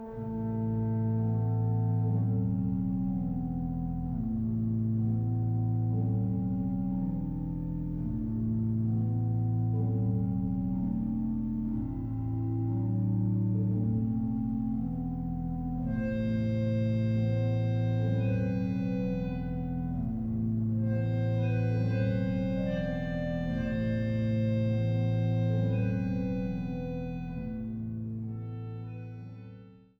Orgel
Stellwagen-Orgel